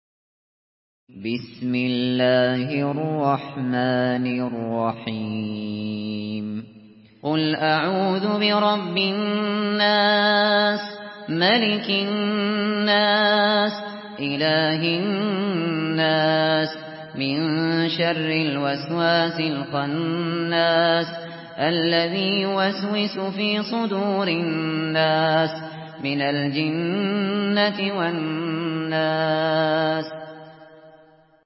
Surah আন-নাস MP3 by Abu Bakr Al Shatri in Hafs An Asim narration.
Murattal Hafs An Asim